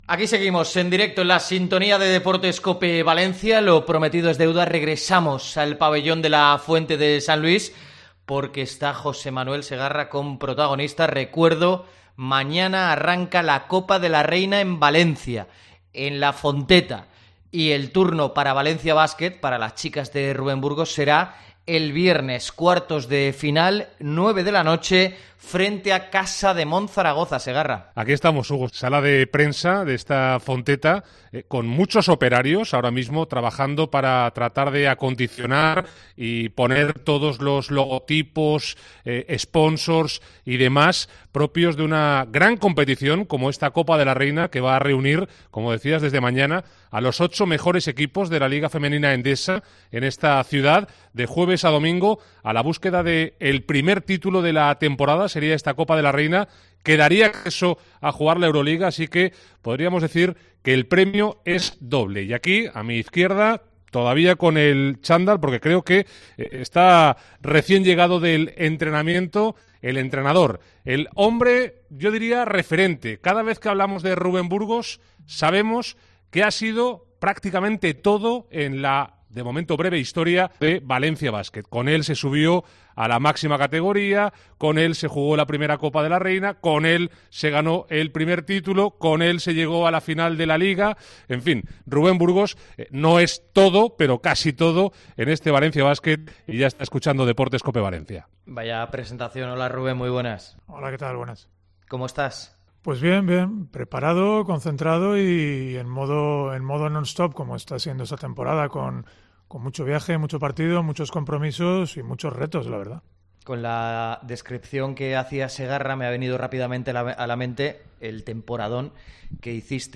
AUDIO. Entrevista